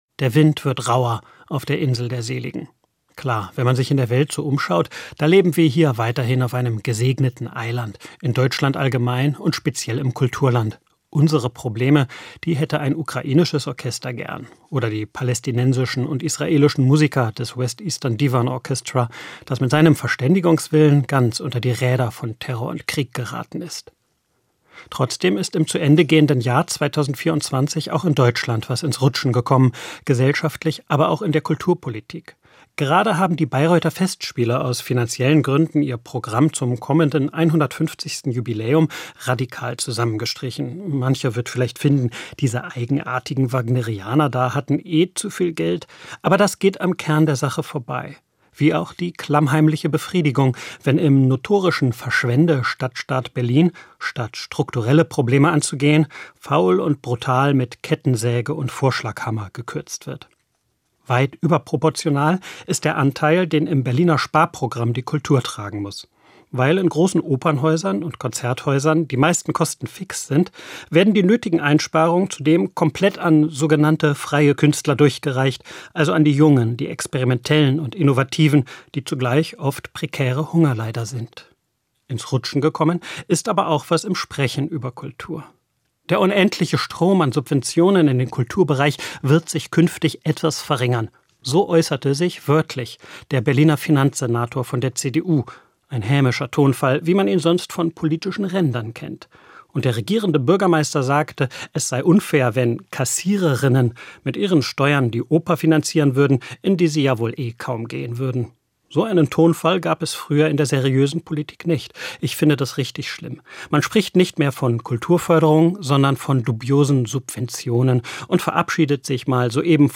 Jahresrückblick